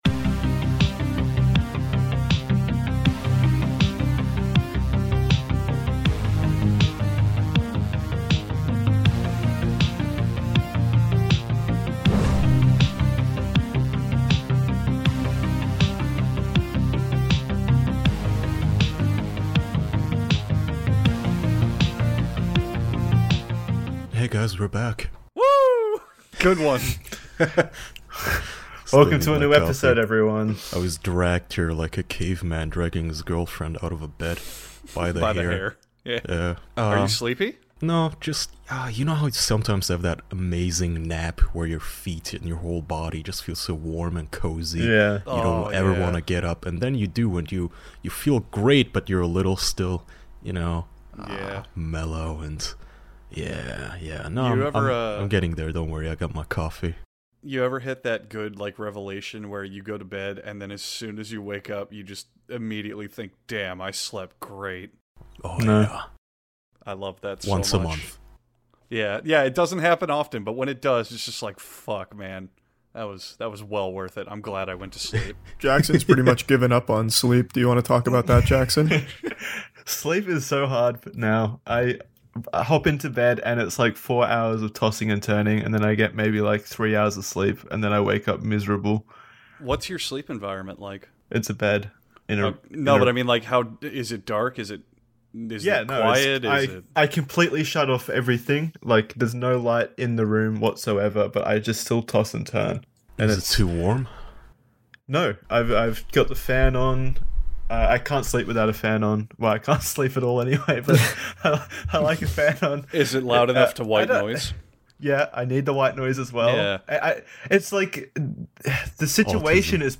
Four close man friends gather around to be peruse Stan Lee's twitter.